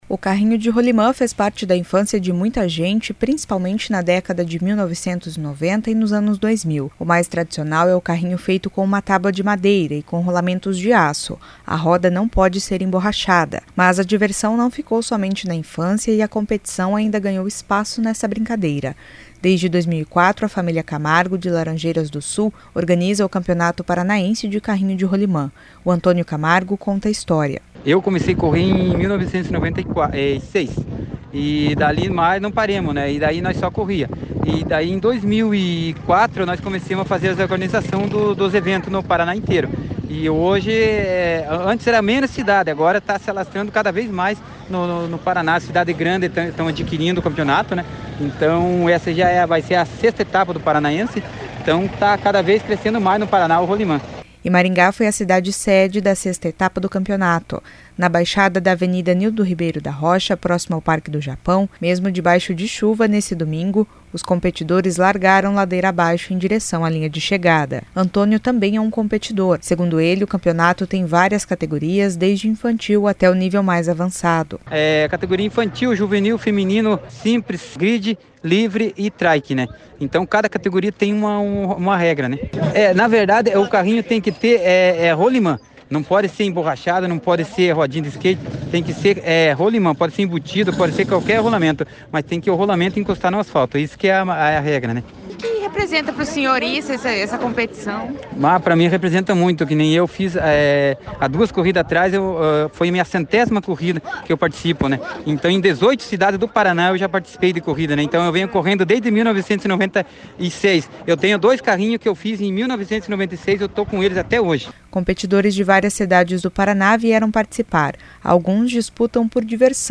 E Maringá foi a cidade sede da 6ª etapa do campeonato. Na baixada da Avenida Nildo Ribeiro da Rocha, próximo ao Parque do Japão, mesmo debaixo de chuva neste domingo (25), os competidores largaram ladeira abaixo em direção a linha de chegada.